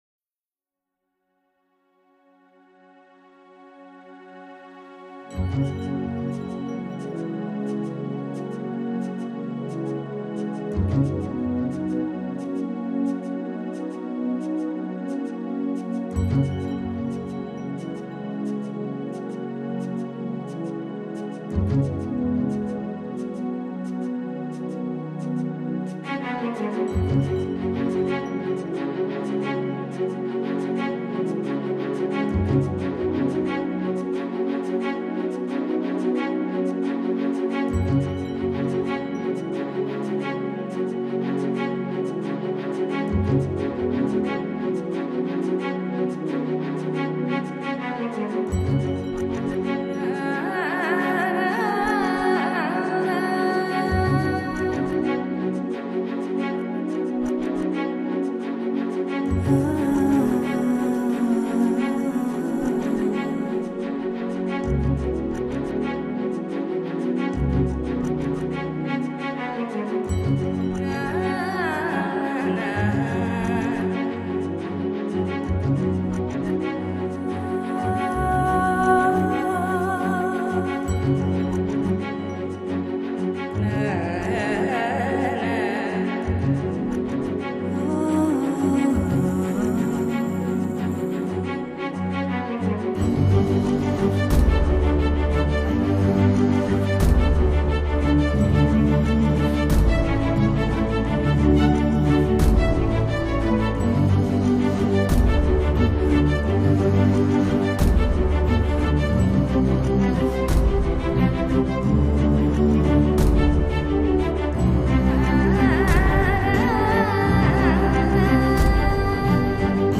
[舒压音乐]
本专缉曲曲精彩，可听性极强，堪称NewAge音乐佳作。